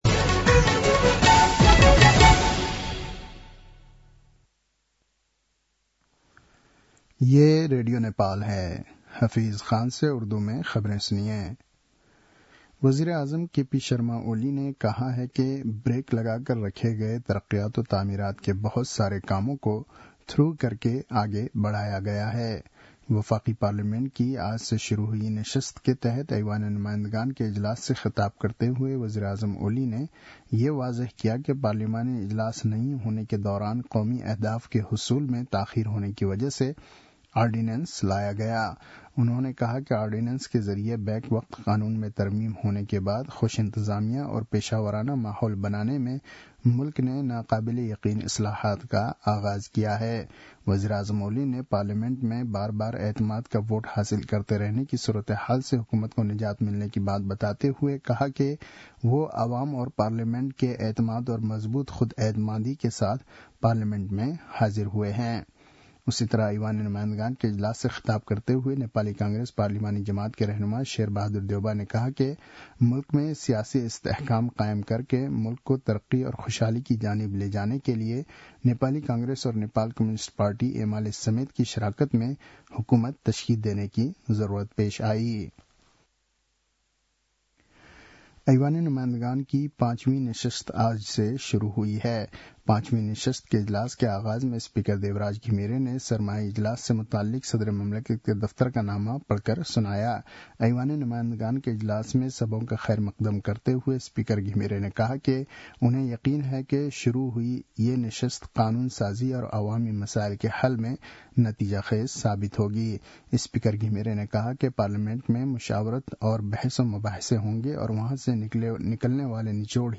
उर्दु भाषामा समाचार : १९ माघ , २०८१
URDU-NEWS-10-18.mp3